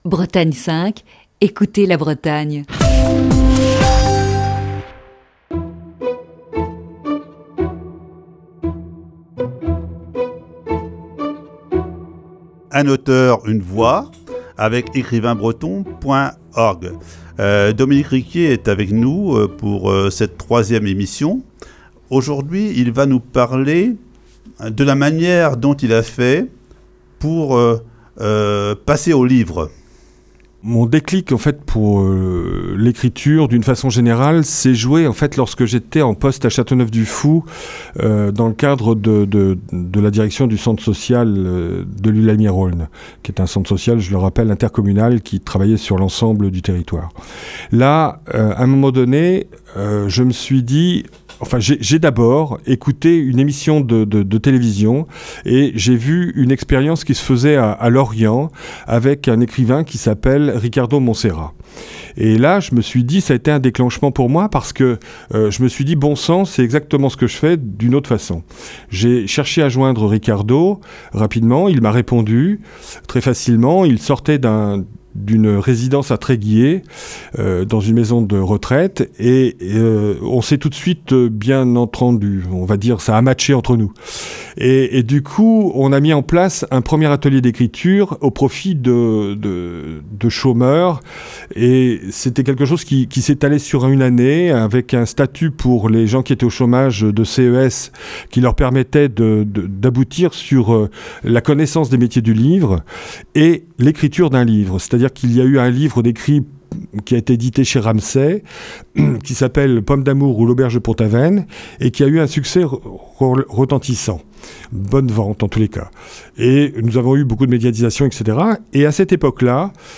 Alors que débute le déconfinement, Un auteur, une voix reste en mode rediffusion.